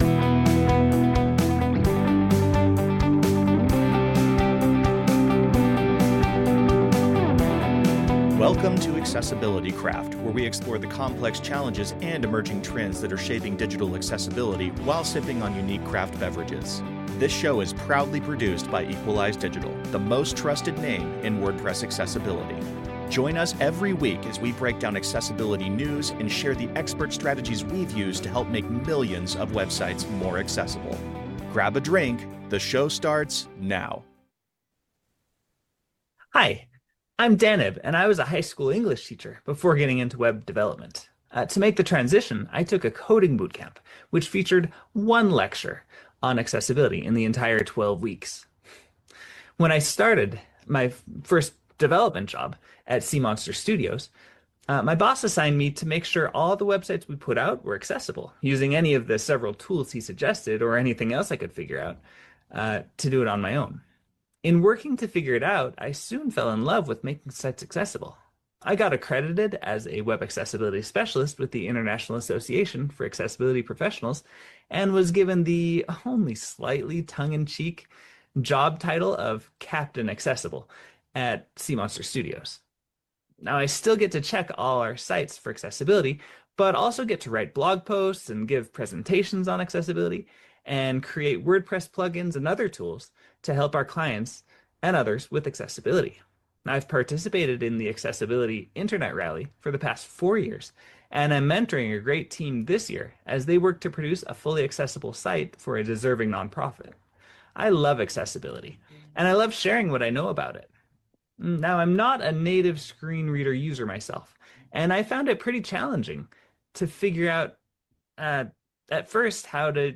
WordPress Accessibility Meetups are a 100% free and virtual community resource that take place via Zoom webinars twice a month.